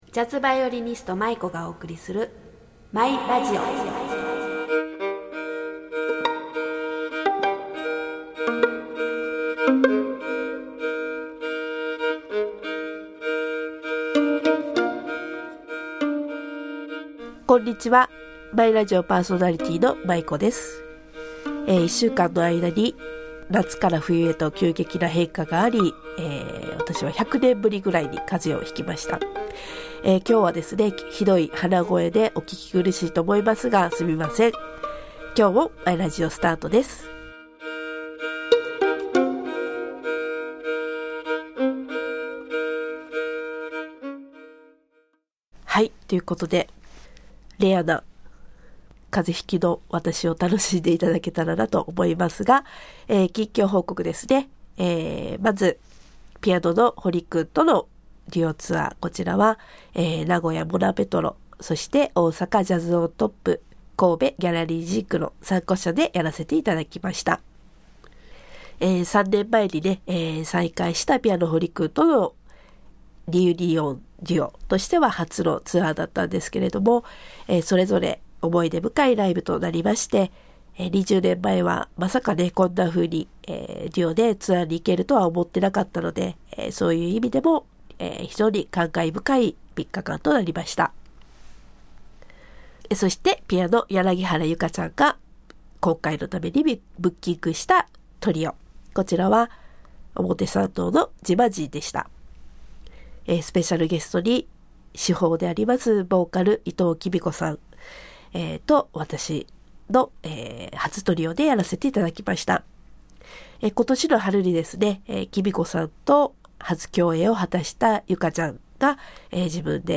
（今回は声がアレですみません…）